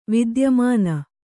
♪ vidyamāna